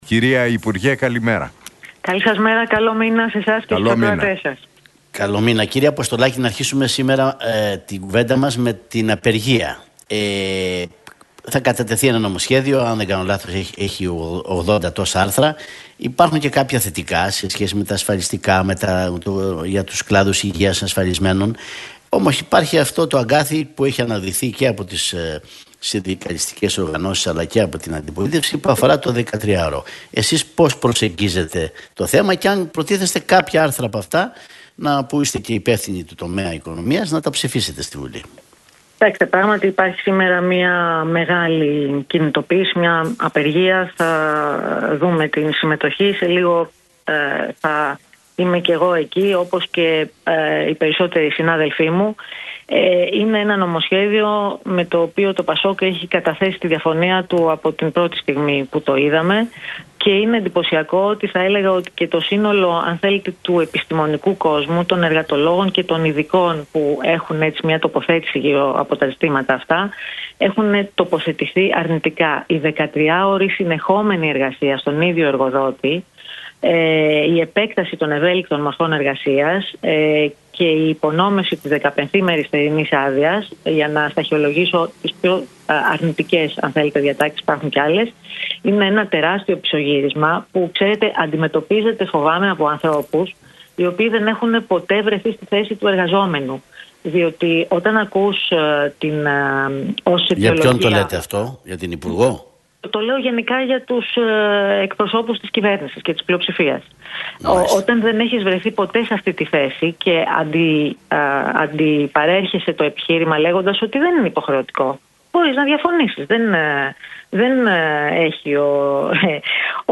Η βουλευτής του ΠΑΣΟΚ, Μιλένα Αποστολάκη, μίλησε στον Realfm 97,8 για το σκάνδαλο του ΟΠΕΚΕΠΕ και την Εξεταστική Επιτροπή, τονίζοντας τη σημασία της